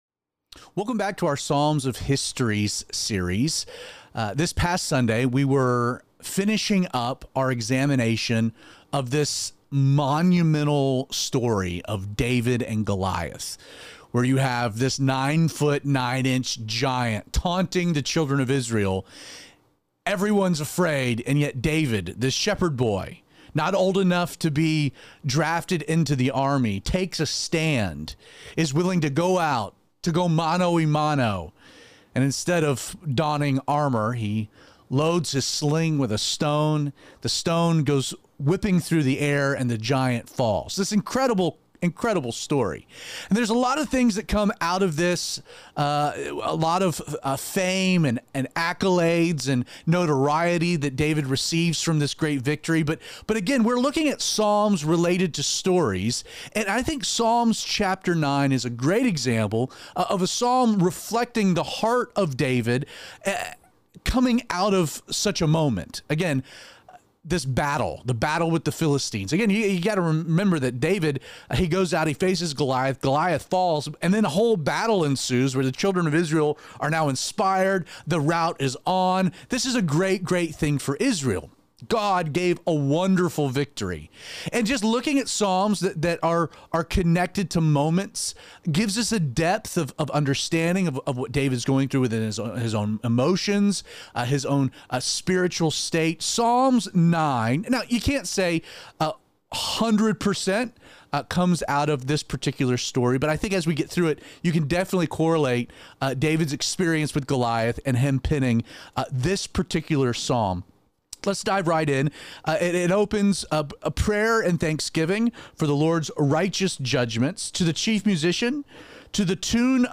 These message are recorded live at Calvary316 on Sunday mornings and posted later that afternoon.